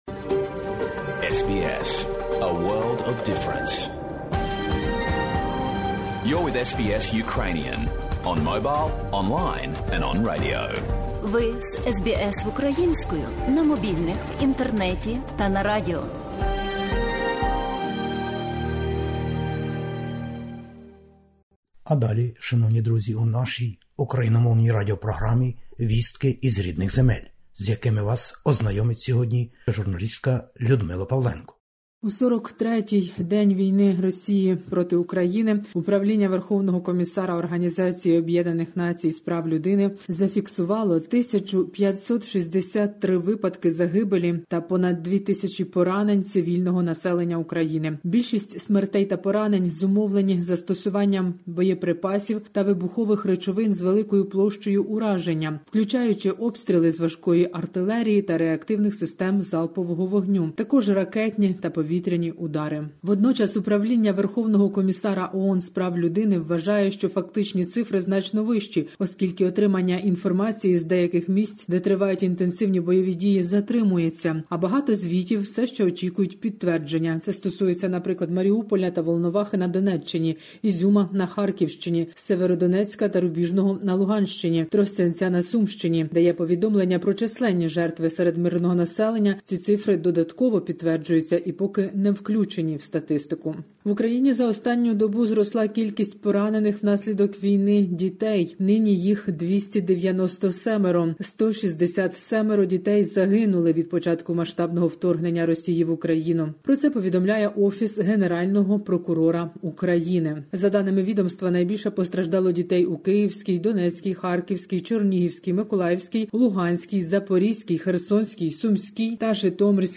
Добірка новин із воюючої України.